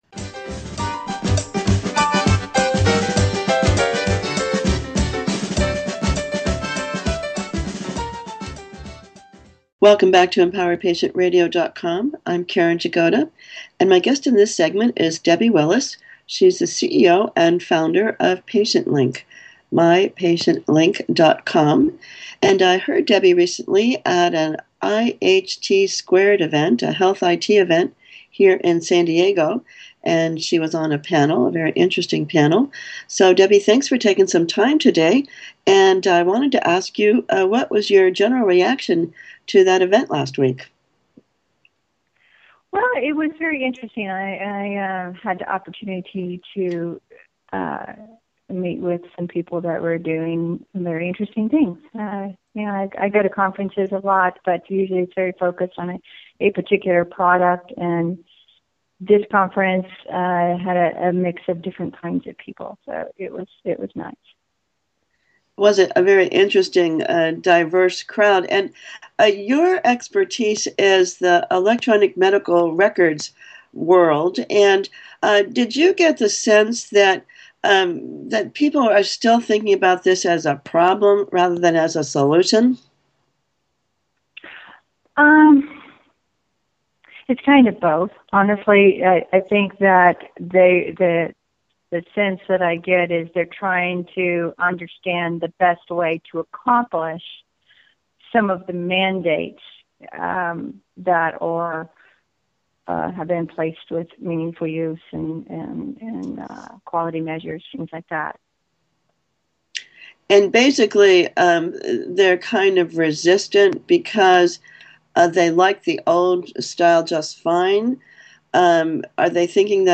Audio interview